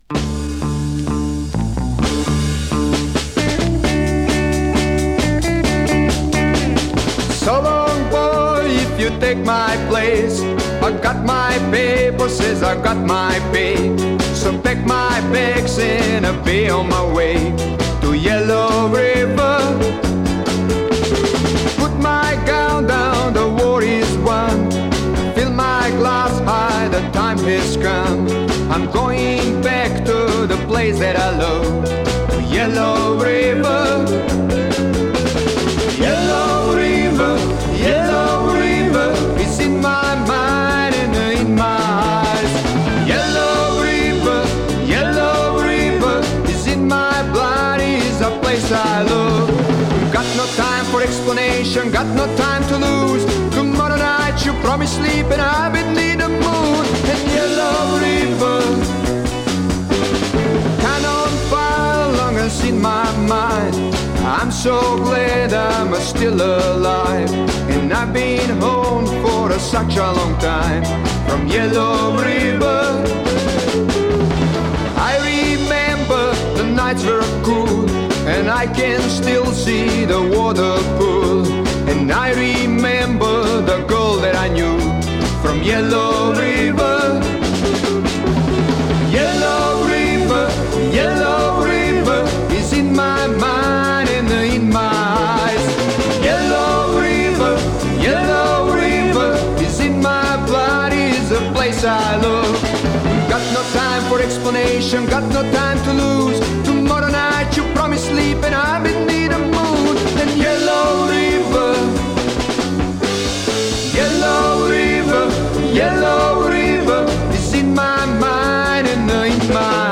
Vocals, Piano, Hammond B3
Vocals, Bass
Drums, Percussion
Vocals, Sacophon, Flute
Vocals, Guitars